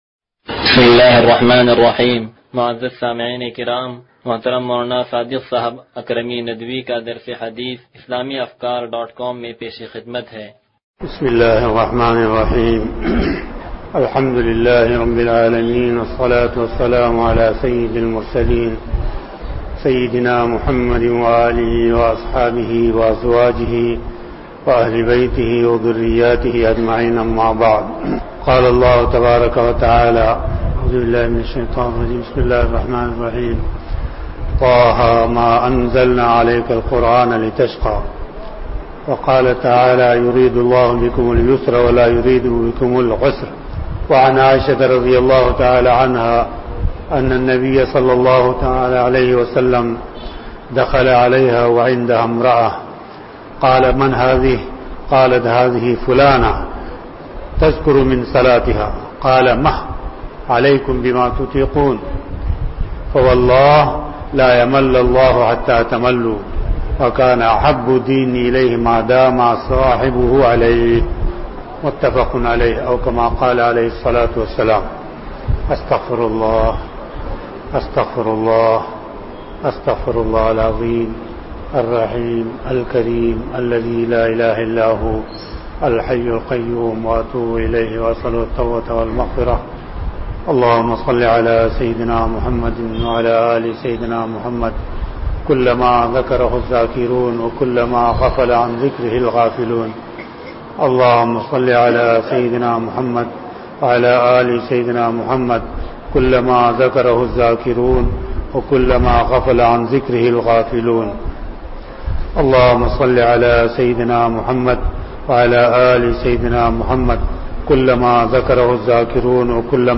درس حدیث نمبر 0146